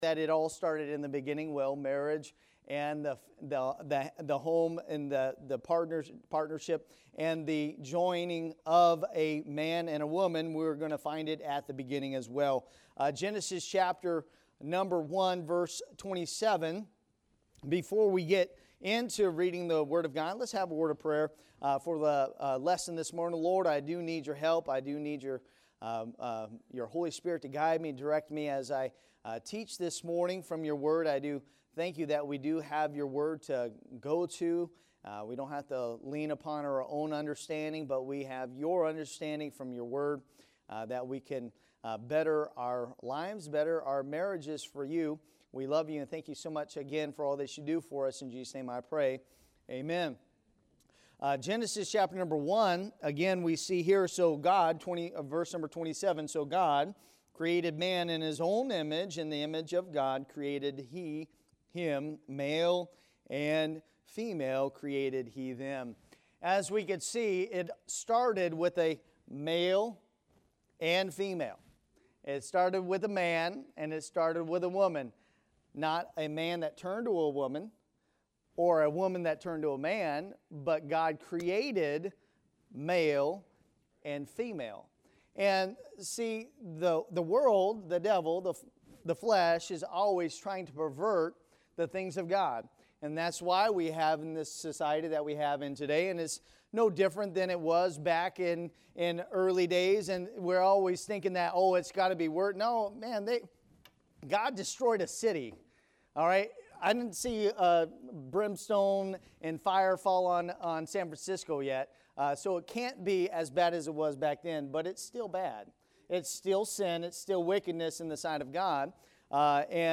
Family | Sunday School